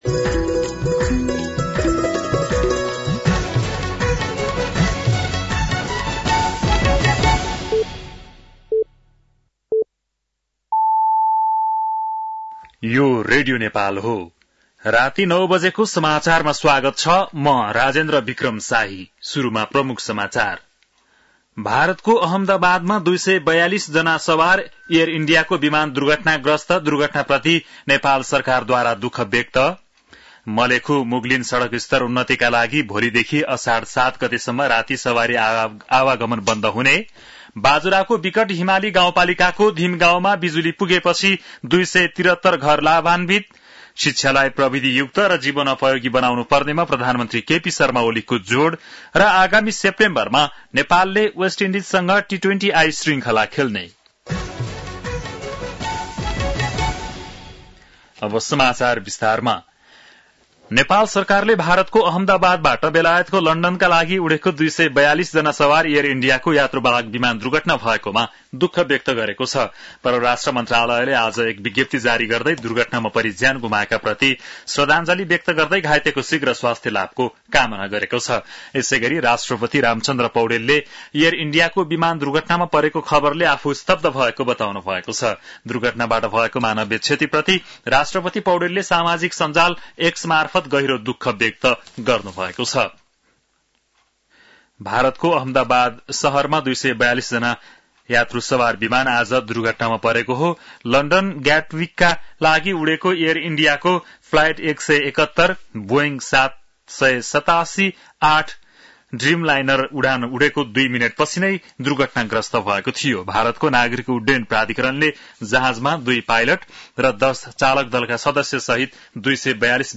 बेलुकी ९ बजेको नेपाली समाचार : २९ जेठ , २०८२
9-PM-Nepali-NEWS-02-29.mp3